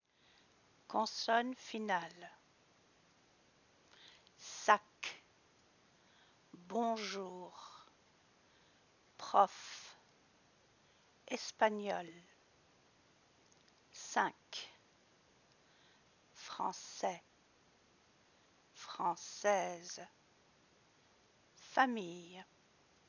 Final Consonants - Consonnes finales
Words ending with one of the consonants in the word ‘careful’ typically have a final consonant that is pronounced.
/sak/
1.2-consonnes-finales.mp3